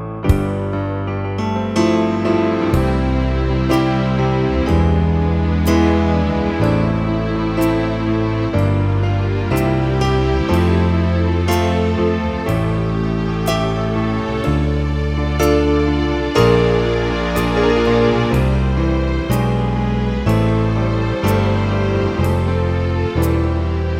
no piano Version 2 Pop (1980s) 5:20 Buy £1.50